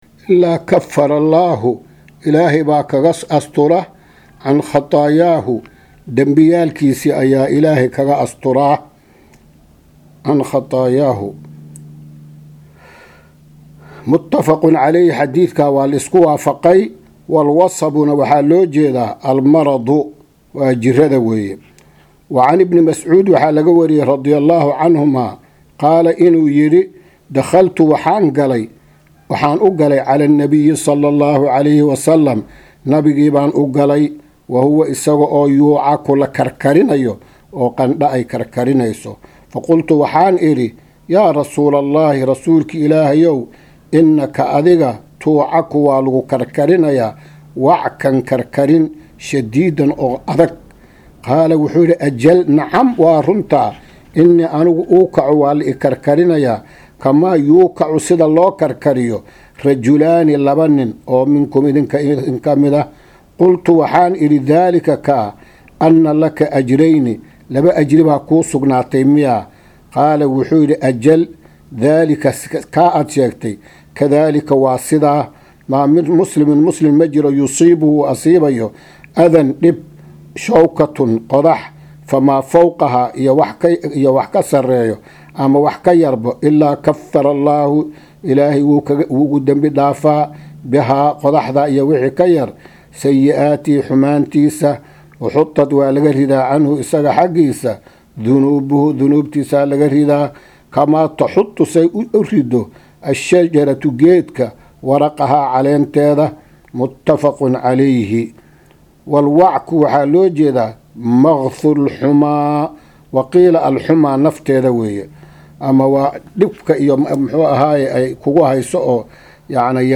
Maqal- Riyaadu Saalixiin – Casharka 7aad
casharka-7aad-ee-Riyad-al-salixiin.mp3